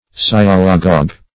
Search Result for " sialogogue" : The Collaborative International Dictionary of English v.0.48: Sialogogue \Si*al"o*gogue\, n. [Gr. si`alon saliva + ???? leading, from ??? to lead: cf. F. sialagogue.]